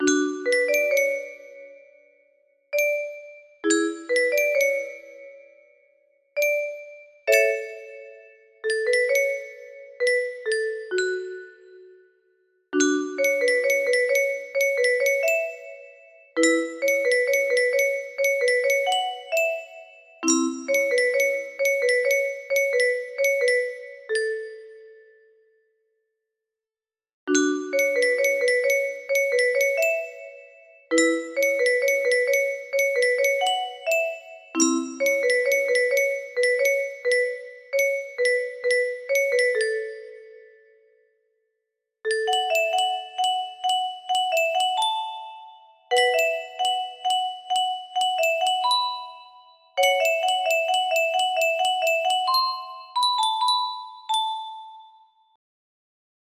1-16 music box melody